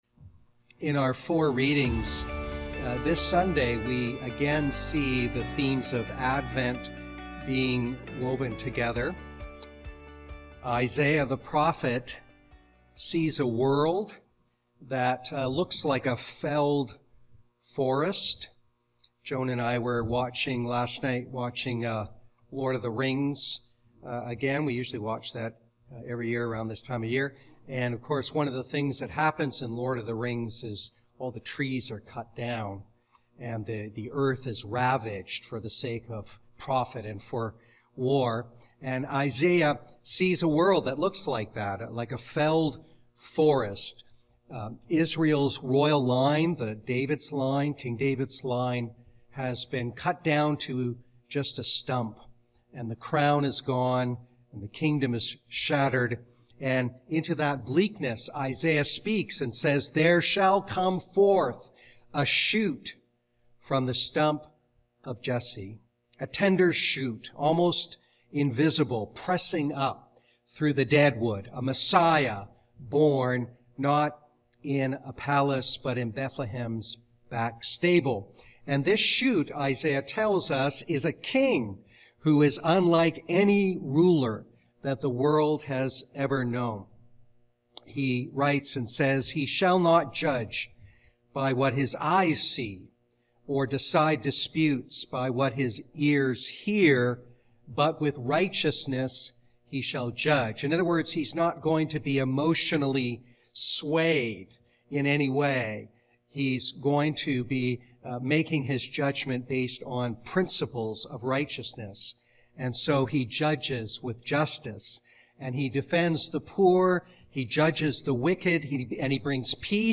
Sermons | Church of the Ascension